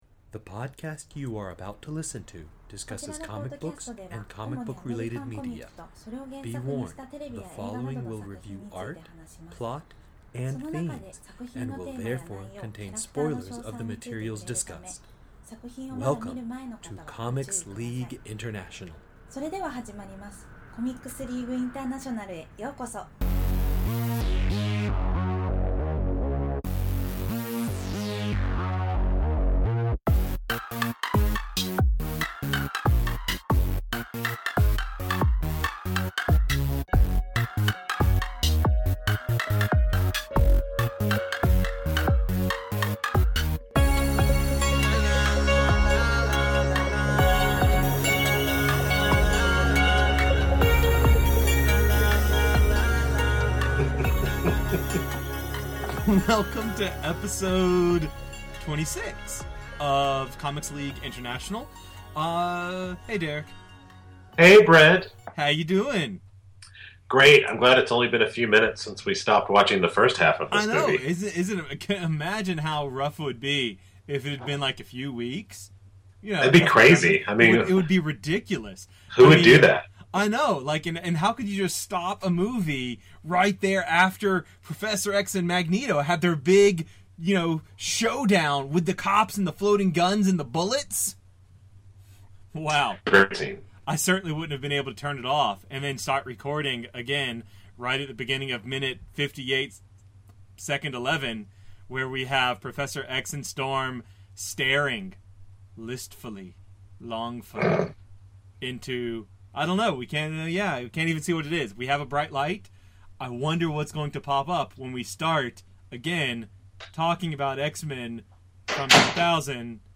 CLI 26: X-Men (the movie) Commentary, pt2
cli-26-x-men-the-movie-commentary-part-2.mp3